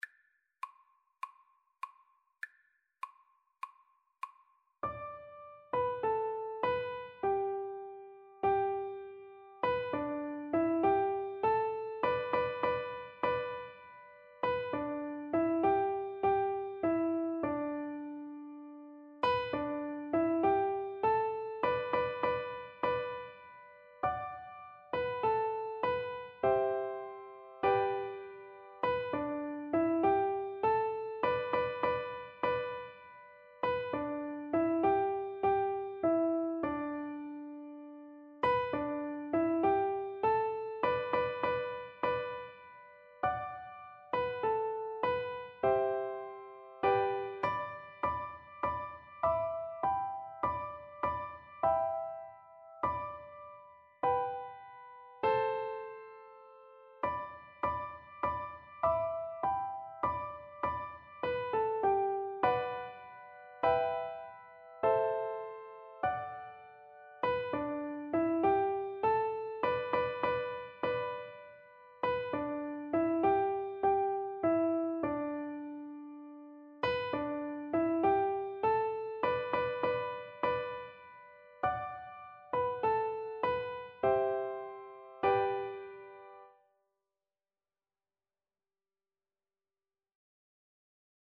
Free Sheet music for Piano Four Hands (Piano Duet)
G major (Sounding Pitch) (View more G major Music for Piano Duet )
4/4 (View more 4/4 Music)